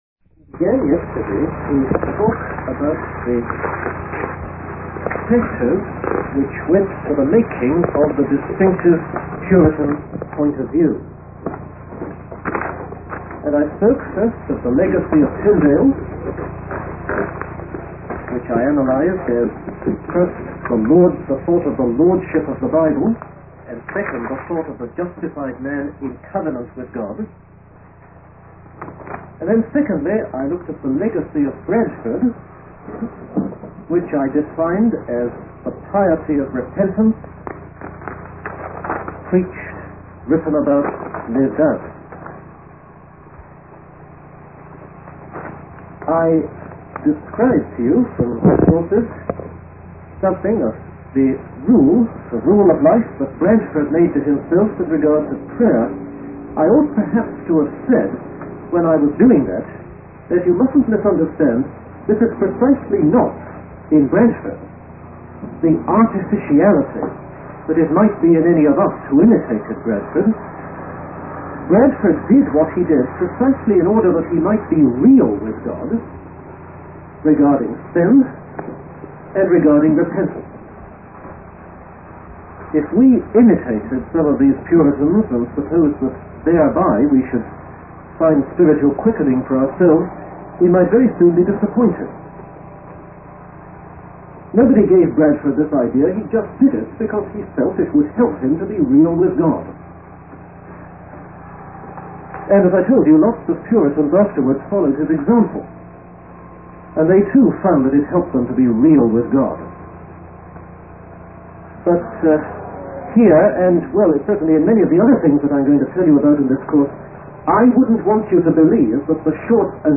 In this sermon, the speaker discusses the importance of following the examples and precepts set forth in the Bible. He highlights the actions of Bishop Hooper, who was unwilling to do anything without a biblical example or precept.